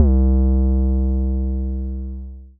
Major Mid Sub.wav